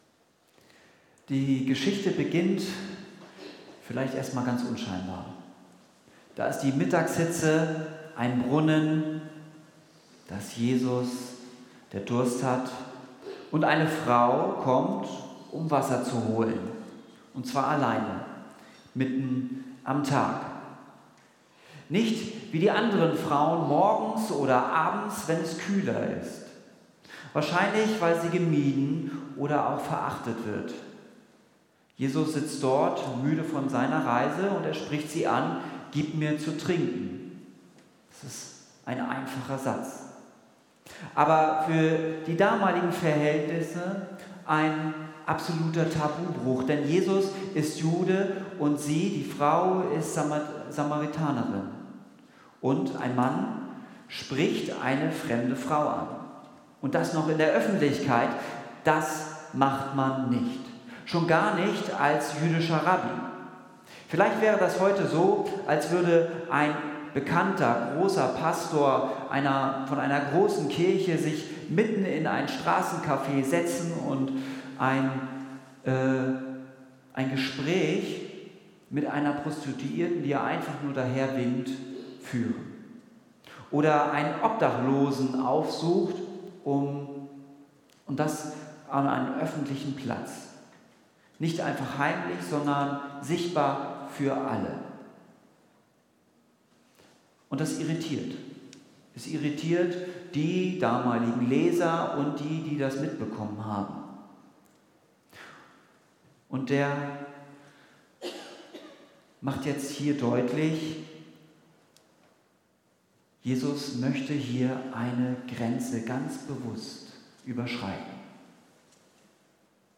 Gottesdienst mit Abendmahl
Predigt